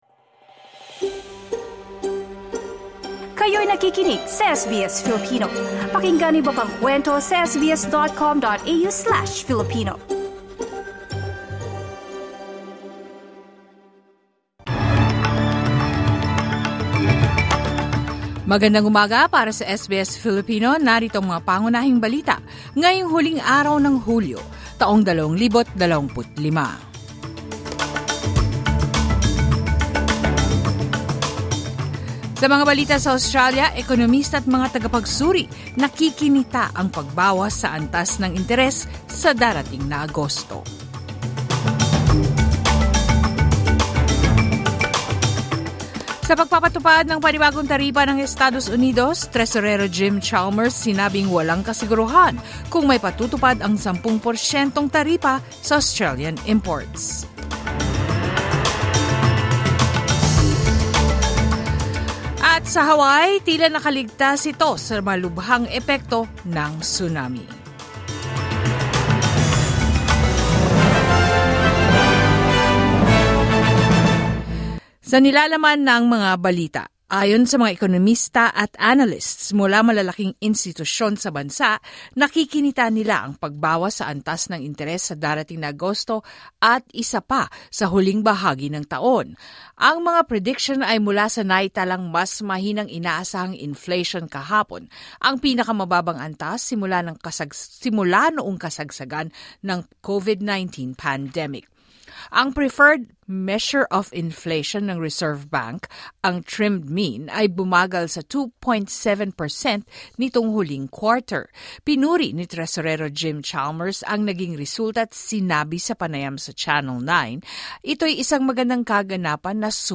SBS News in Filipino Thursday, 31 July 2025